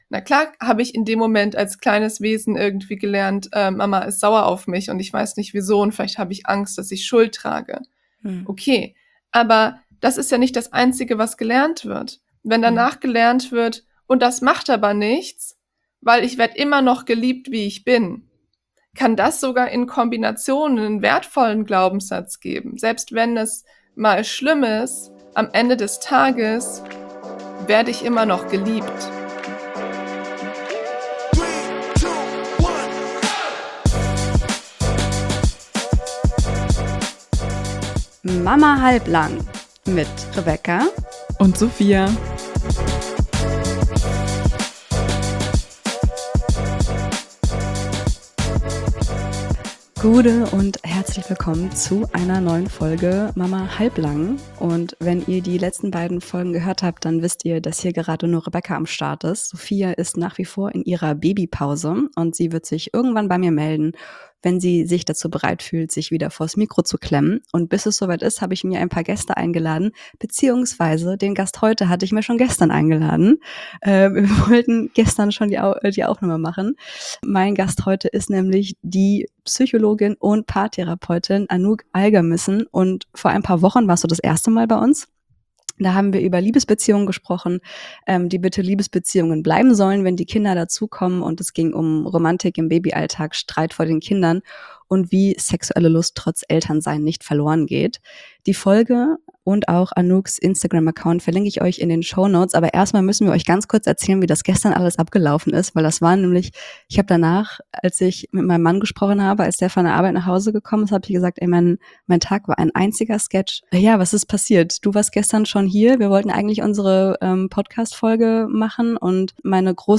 SPECIAL: Eltern werden, Paar bleiben – Interview mit Paartherapeutin über Streit vorm Kind, Sexualität und Mental Load